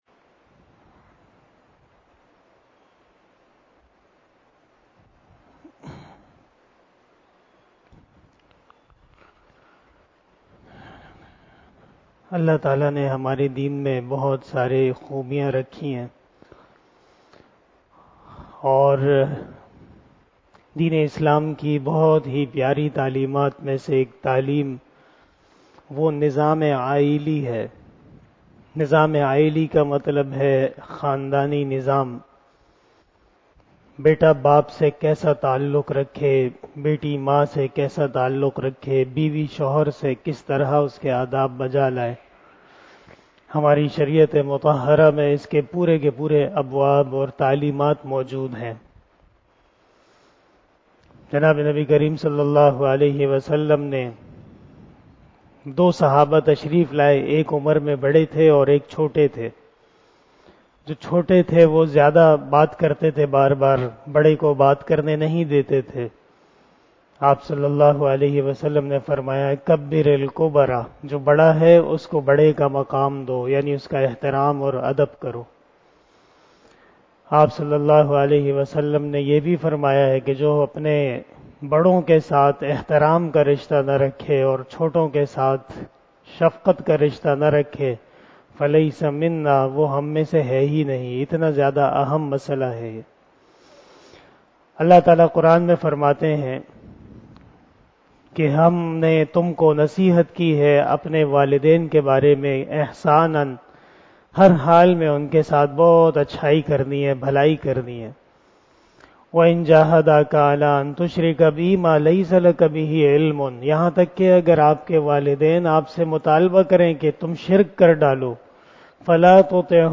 049 After Asar Namaz Bayan 20 April 2022 ( 19 Ramadan 1443HJ) Wednesday